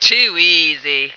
flak_m/sounds/female2/int/F2toeasy.ogg at 46d7a67f3b5e08d8f919e45ef4a95ee923b4048b